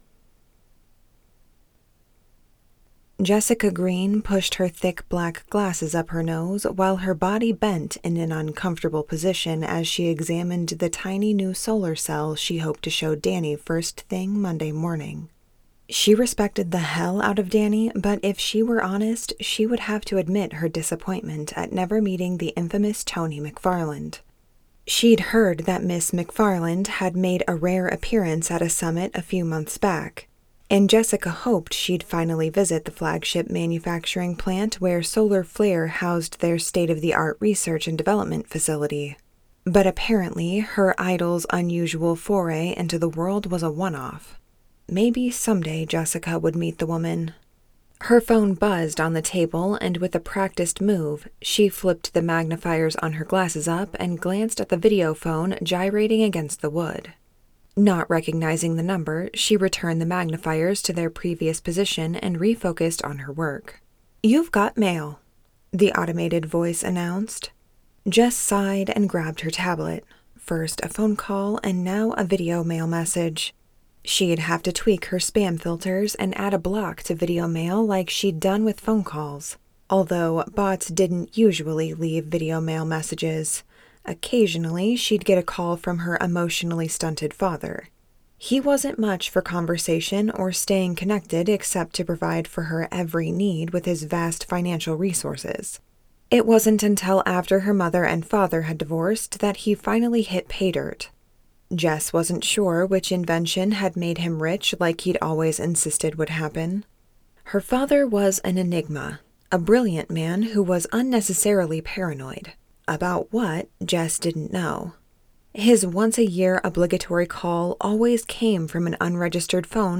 Love Sins by Annette Mori Book III in The Next Generation Series [Audiobook]